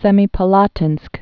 (sĕmē-pə-lätĭnsk)